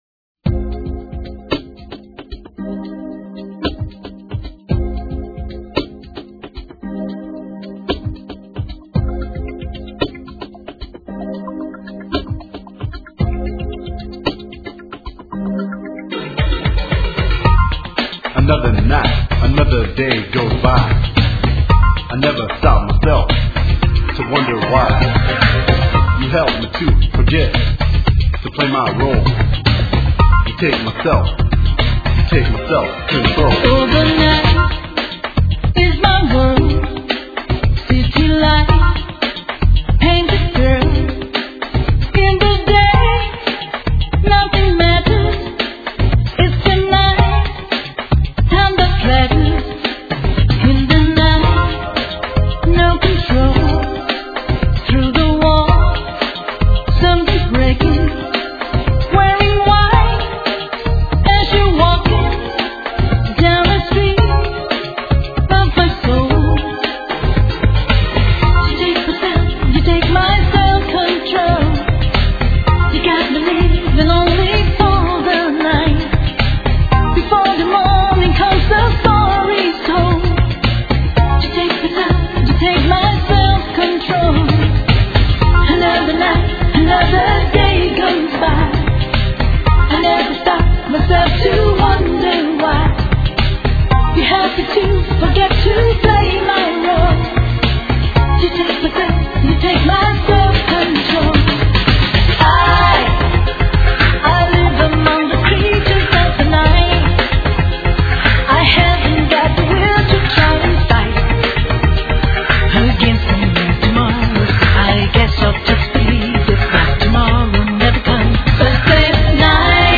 * Thể loại: Nhạc Ngoại Quốc